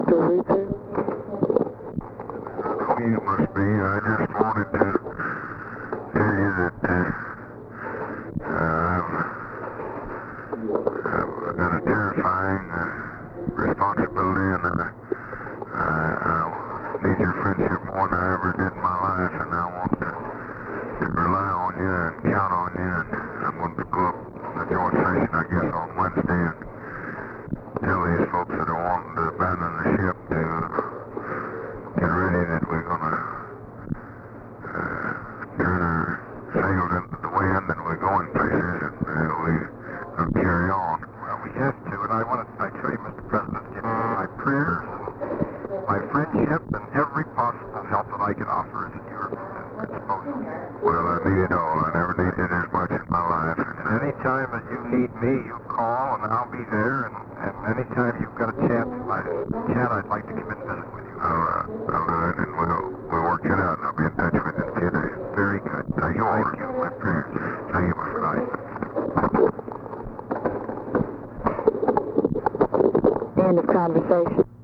Conversation with WALTER REUTHER, November 23, 1963
Secret White House Tapes